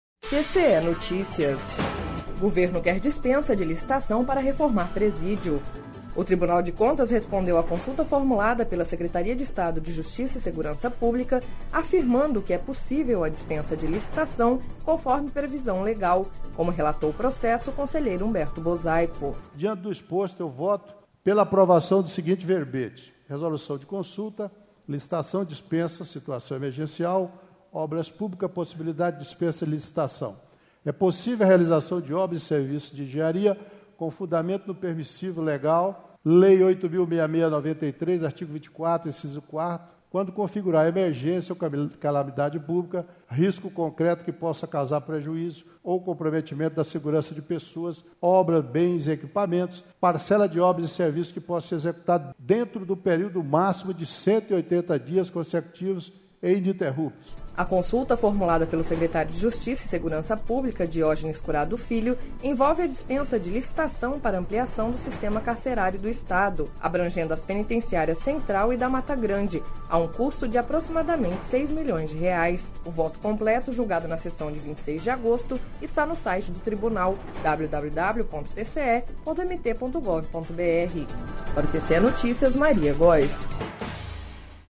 Sonora: Humberto Bosaipo – conselheiro TCE-MT